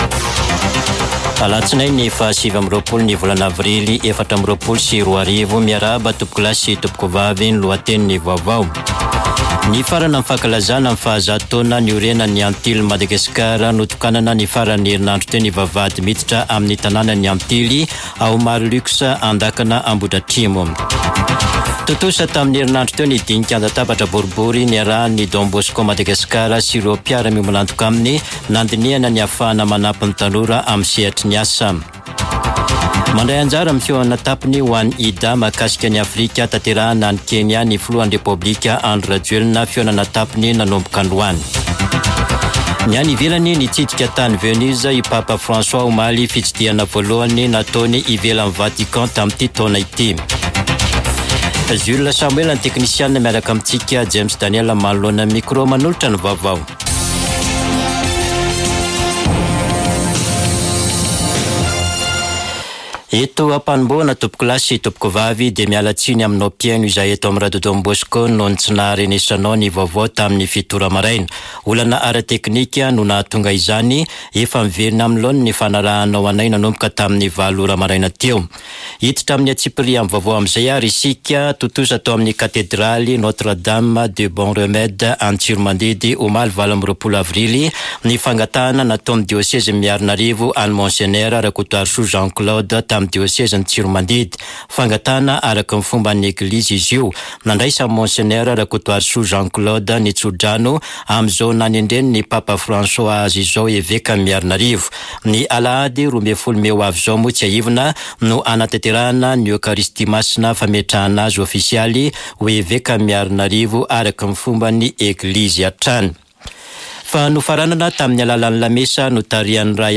[Vaovao antoandro] Alatsinainy 29 aprily 2024